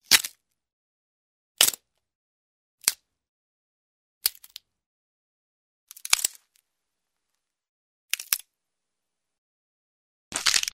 Звуки трещины
На этой странице собраны разнообразные звуки трещин — от ломающегося льда до скрипа дерева и раскалывающихся материалов.